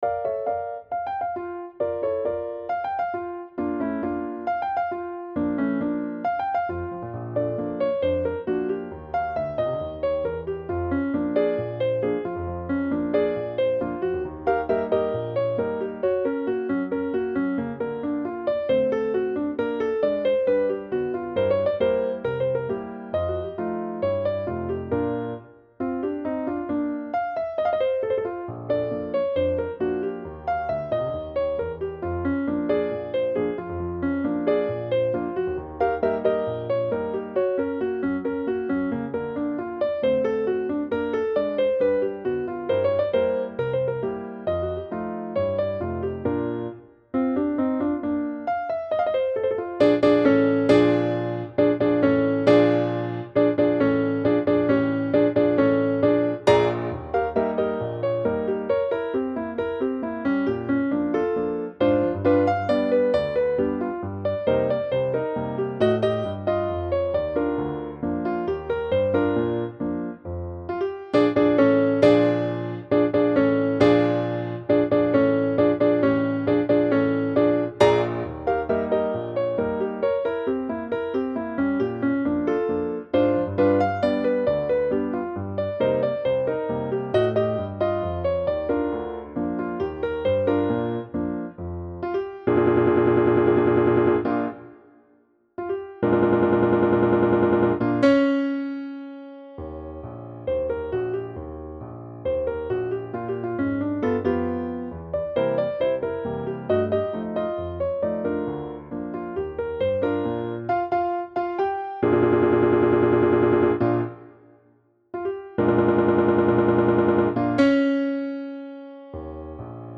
Stride piano